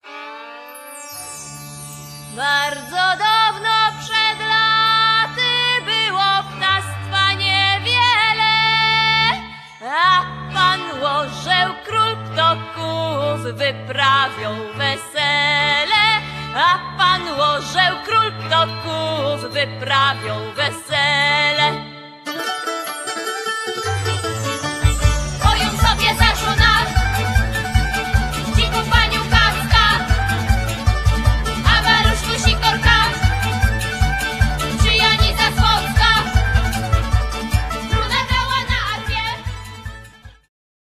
wiolonczela, chórki
bębny, instrumenty perkusyjne, chórki
mandola, dutar, gitara
akordeon